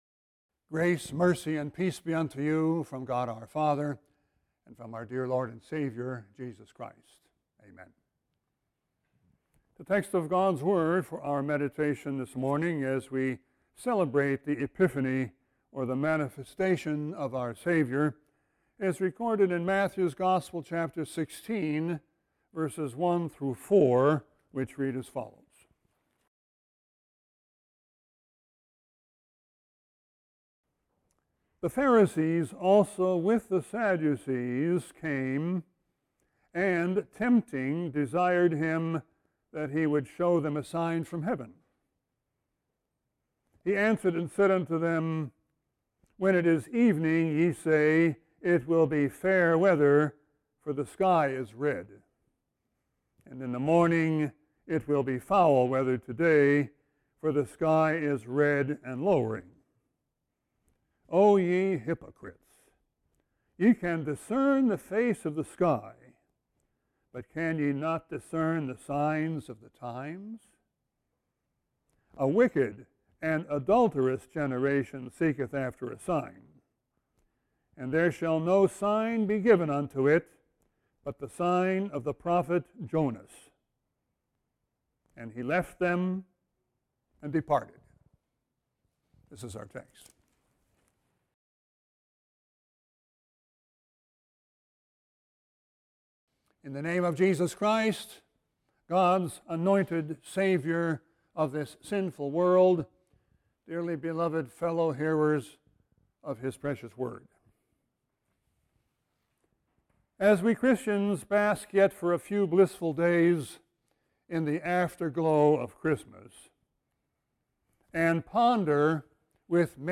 Sermon 1-3-21.mp3